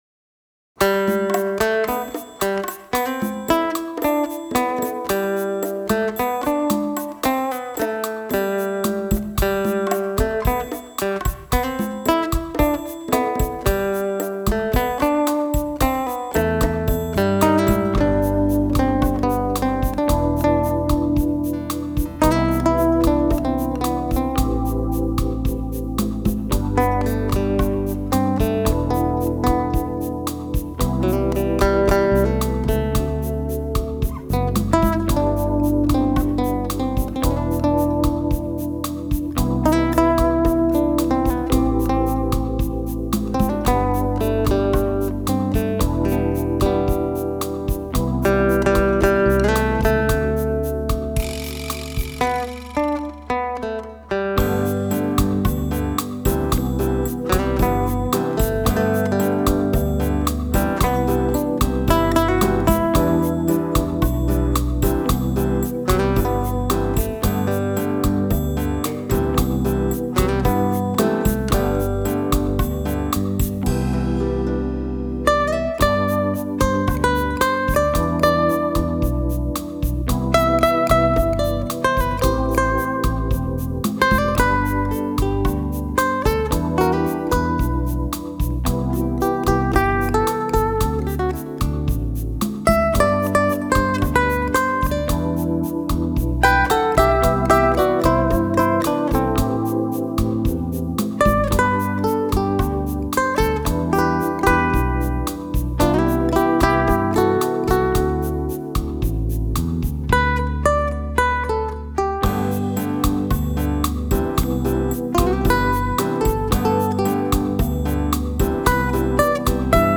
Vancouver Guitarist